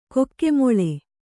♪ kokke moḷe